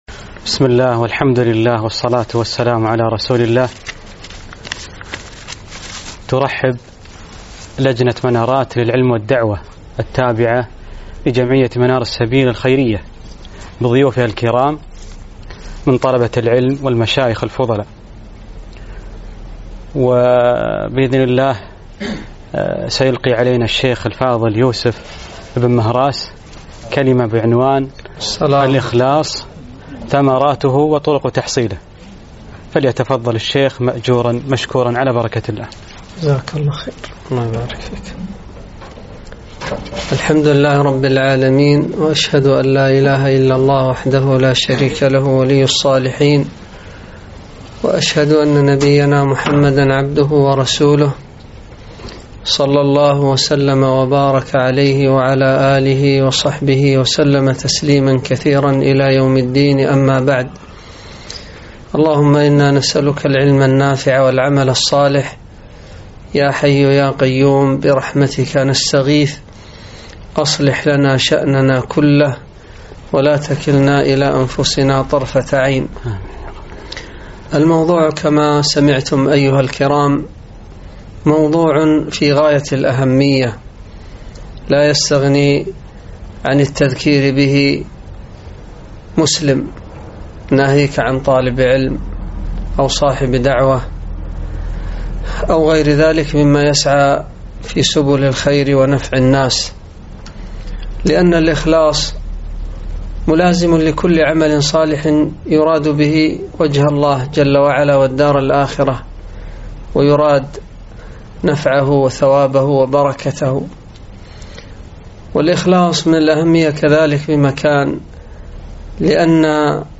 محاضرة - الإخلاص ثمراته وطرق تحصيله